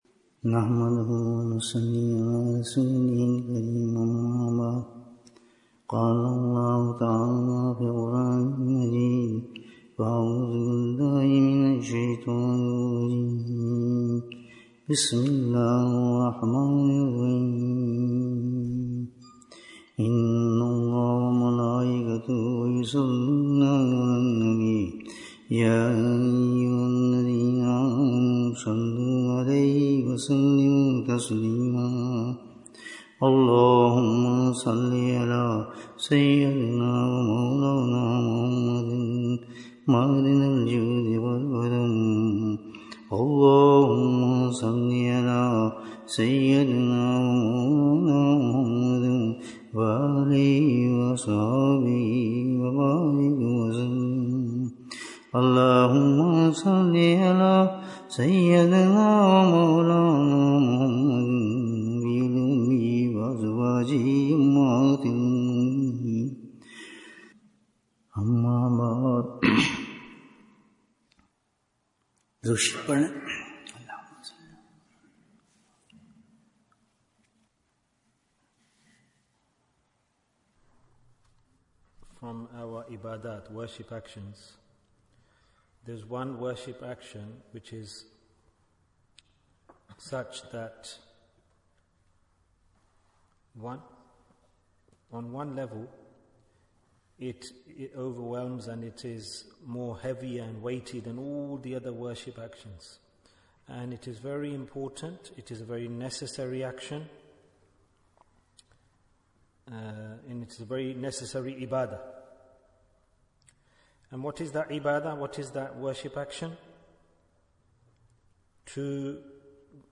What Do We Gain From Dua? Bayan, 38 minutes30th May, 2024